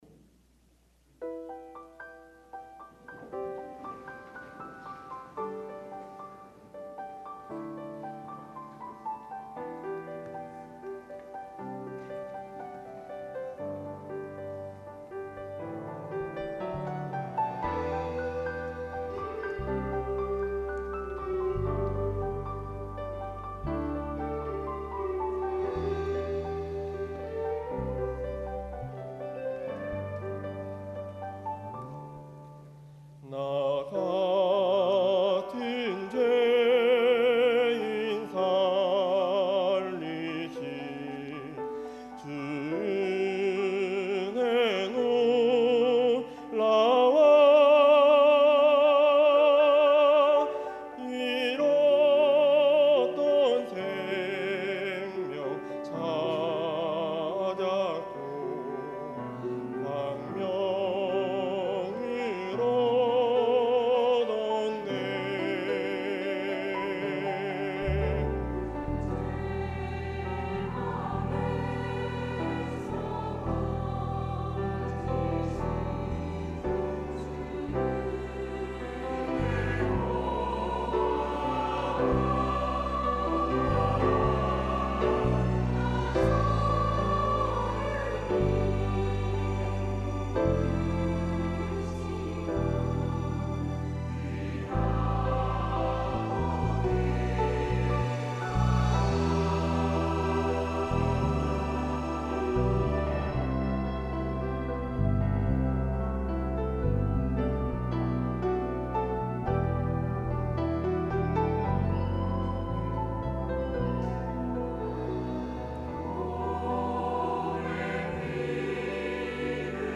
찬양 :: 141019 나 같은 죄인 살리신
" 나 같은 죄인 살리신 "- 시온 찬양대